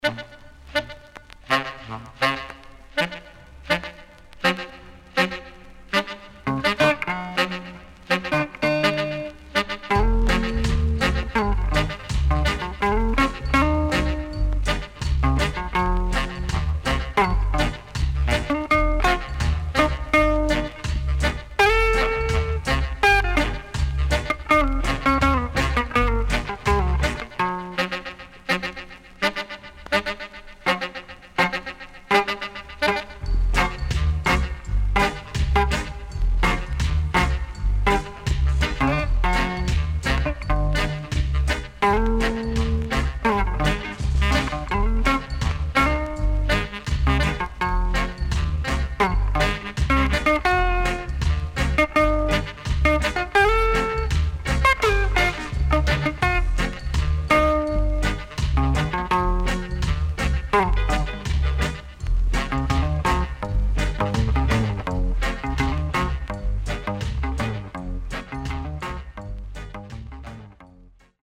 SIDE A:少しチリノイズ入ります。